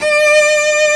Index of /90_sSampleCDs/Roland LCDP13 String Sections/STR_Combos 1/CMB_Lrg Ensemble
STR SOLO C0Z.wav